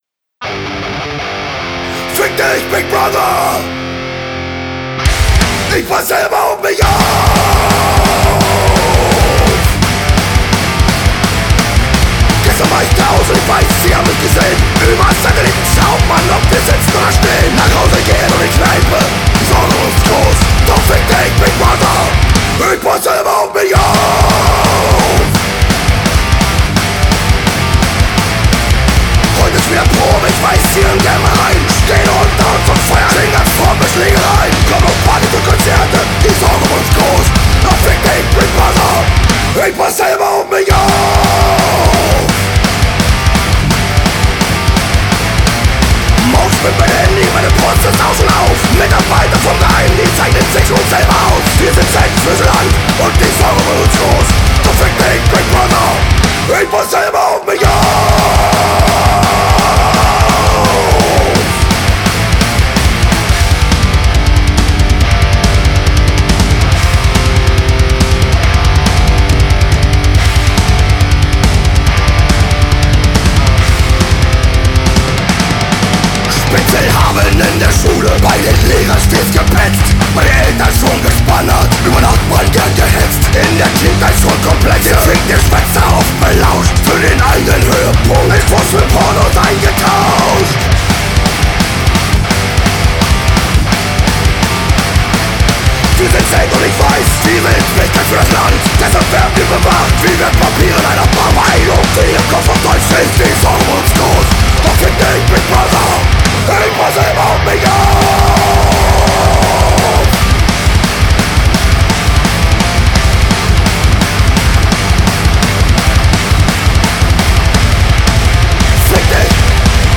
Schön hart gespielte Musik Metalmäßig und HC.